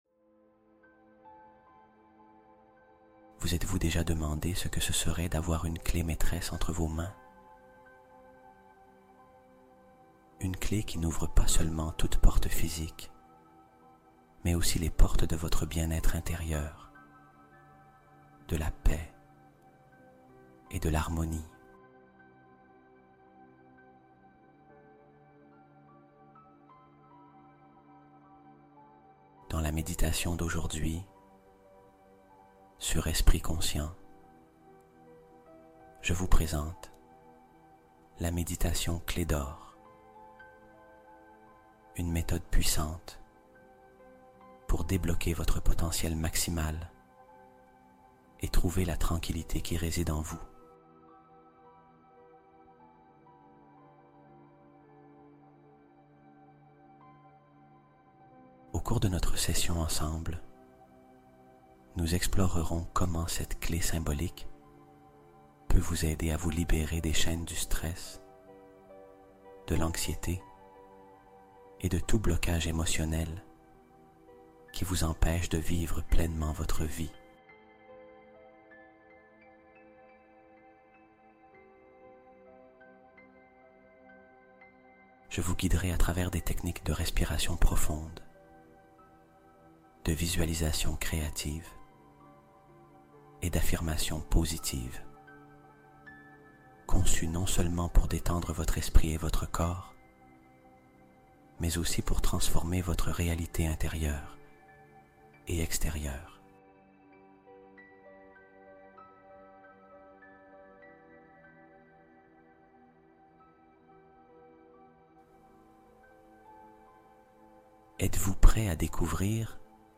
Bond quantique en 8 heures | Hypnose nocturne qui transforme ta réalité